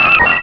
Cri d'Hélédelle dans Pokémon Rubis et Saphir.